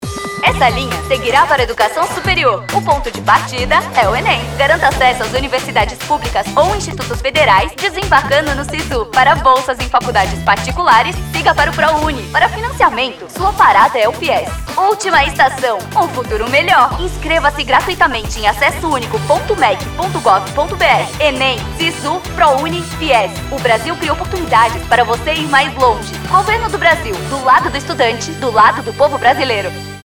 Spot - Campanha Fé no Brasil - Sul - PR